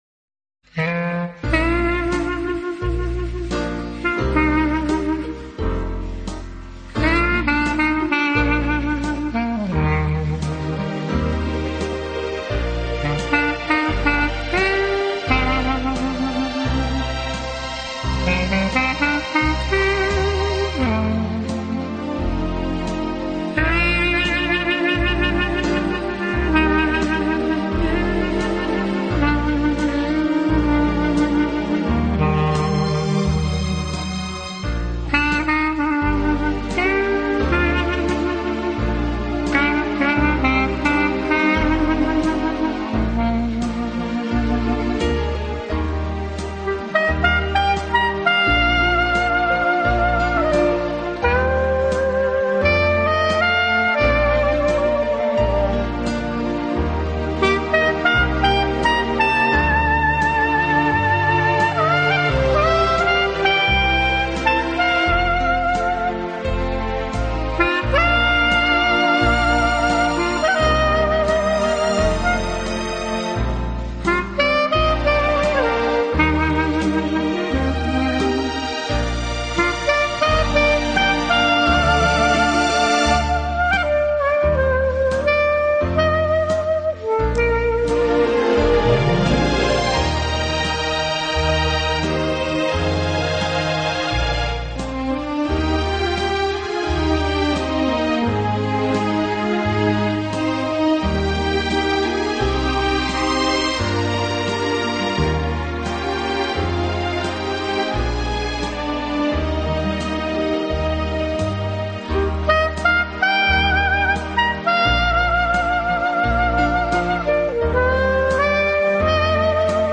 【纯音单簧管】
本套CD音乐之音源采用当今世界DVD音源制作最高标准：96Khz/24Bit取样录制，其音源所
音域宽广的单簧管，高音嘹亮明朗；中音富于表情，音色纯净，清澈优美；低音低沉，浑